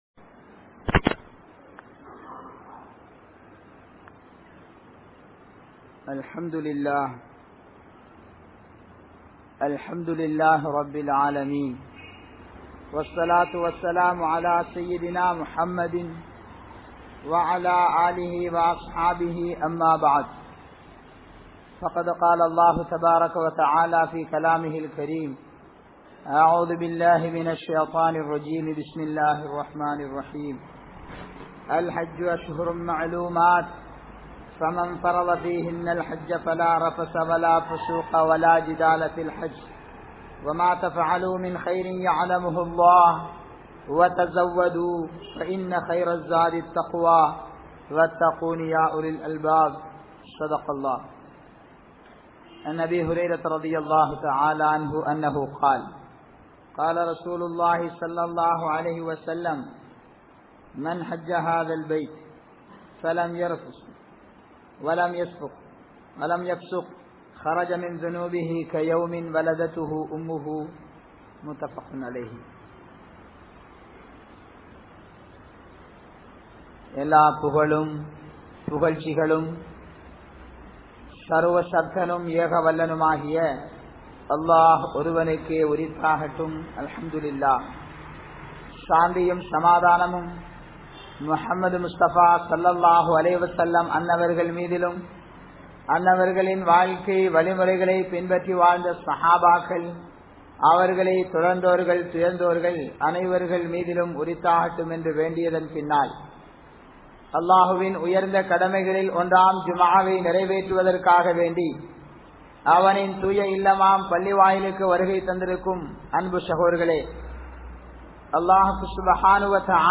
Hajj and Responsibilities of Hajj Operators | Audio Bayans | All Ceylon Muslim Youth Community | Addalaichenai